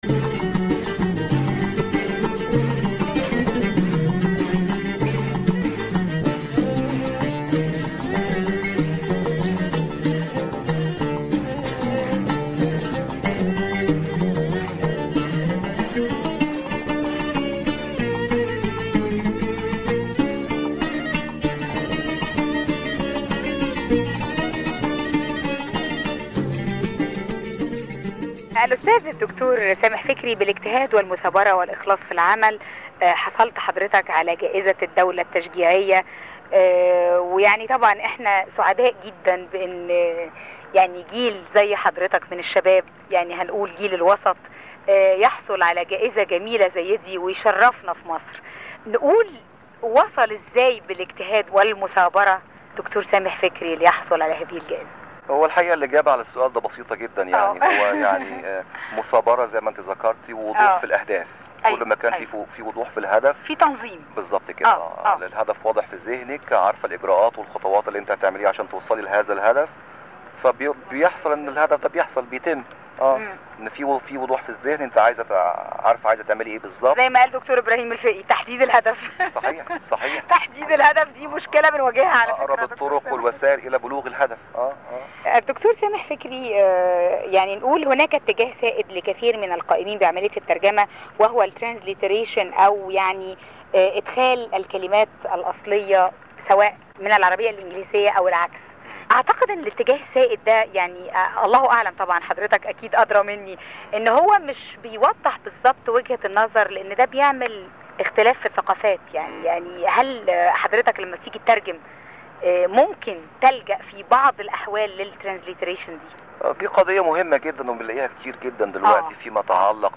Version Radio Interview Part II